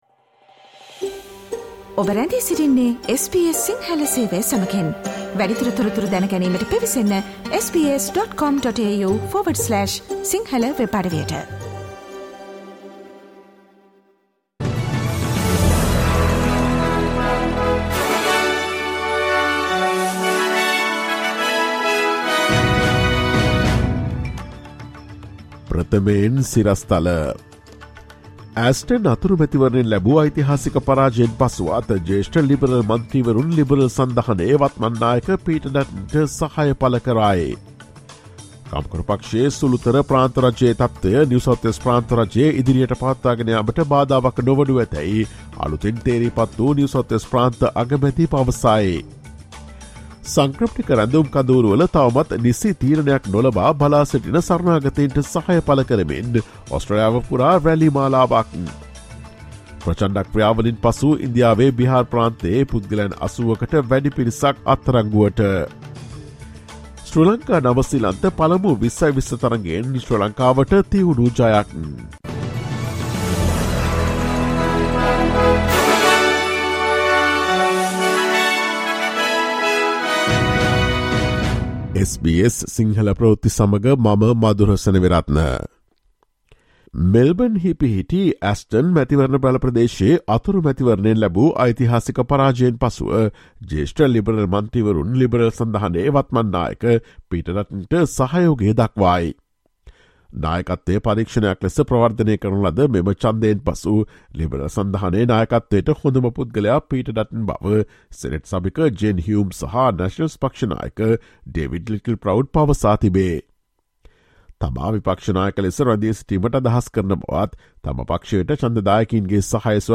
ඔස්ට්‍රේලියාවේ නවතම පුවත් මෙන්ම විදෙස් පුවත් සහ ක්‍රීඩා පුවත් රැගත් SBS සිංහල සේවයේ 2023 අප්‍රේල් 03 වන දා සඳුදා වැඩසටහනේ ප්‍රවෘත්ති ප්‍රකාශයට සවන් දෙන්න.